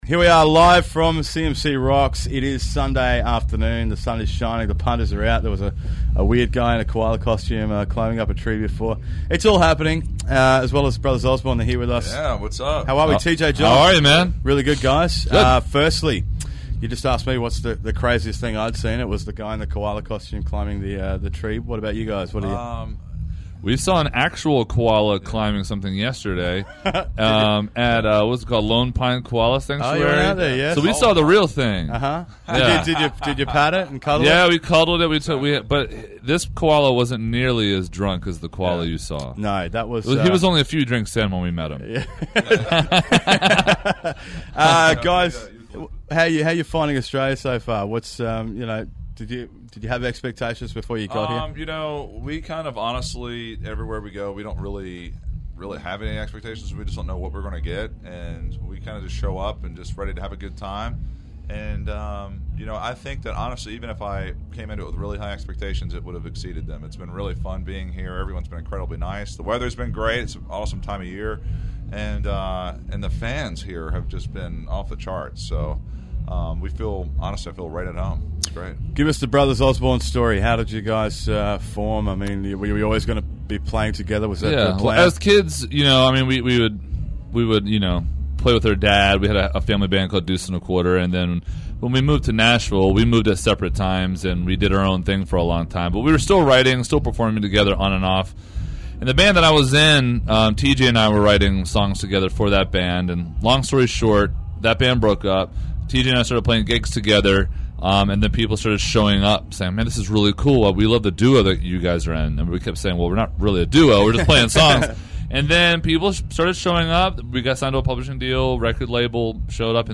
interviews
live from CMC Rocks